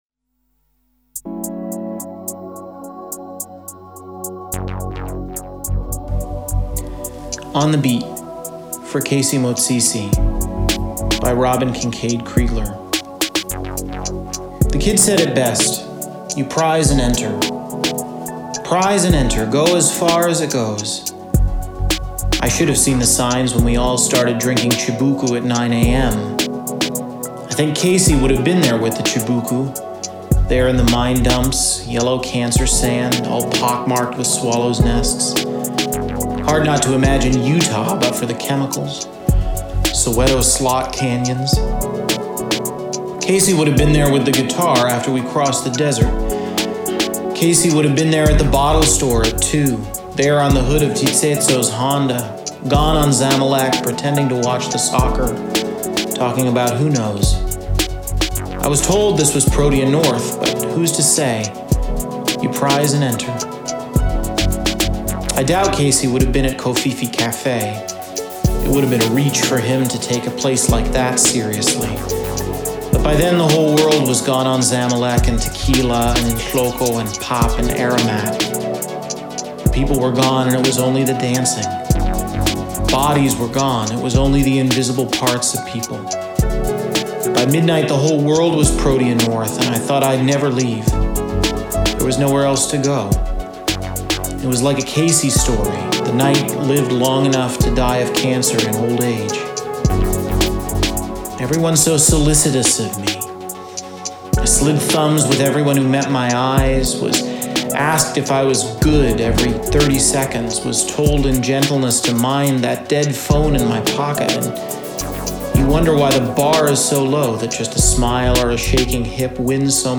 A collection of textured beats featuring artists’ contributions to Ons Klyntji 2023.